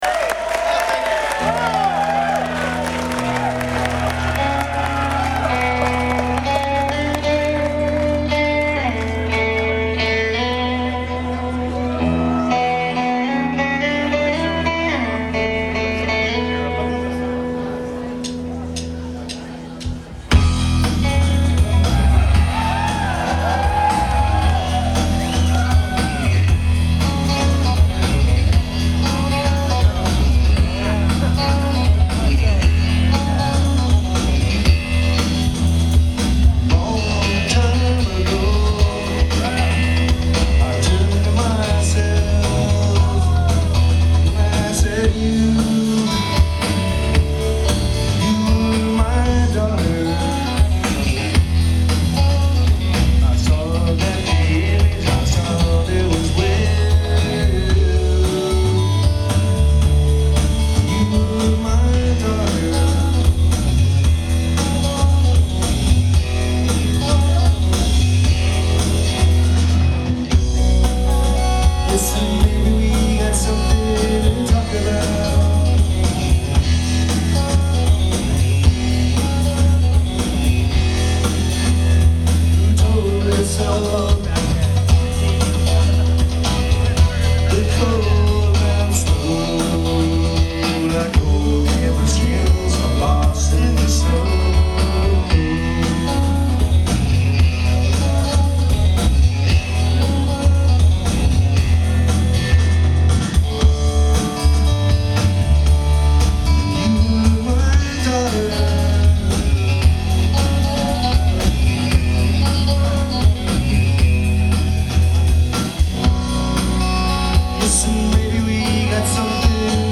Mic: Sony ECM MS907 (90-degree setting)
Mic config: handheld, chest level, pointed at center stage
Location: FOB, floor middle, 25' back from stage
Source:  Sony MZ-R37 MD Recorder